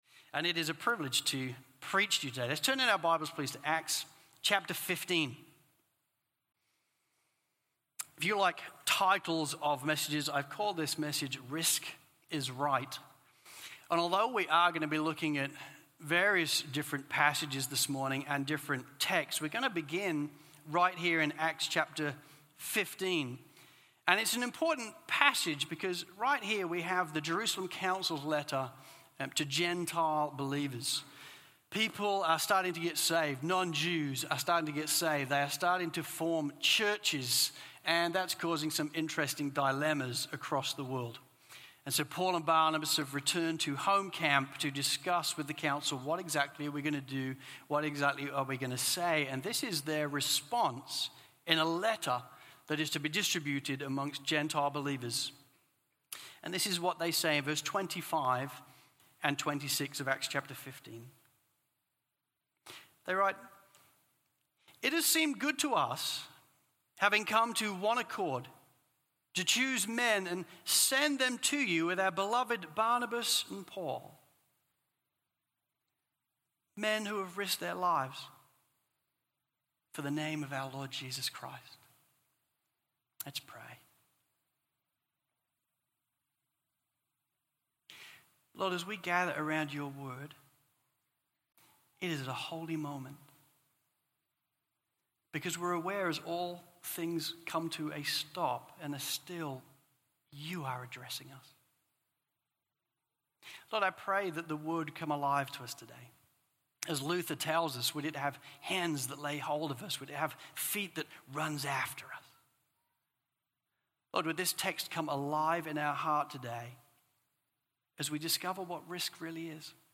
Risk is Right – Stand Alone Sermons | Crossway Community Church